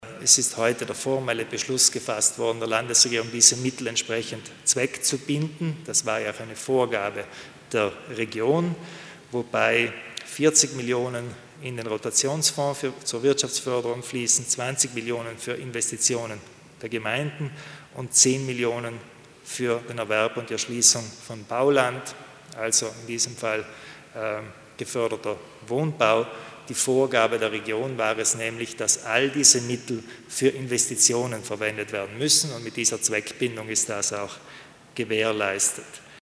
Landeshauptmann Kompatscher erklärt wozu die Gelder der Region eingesetzt werden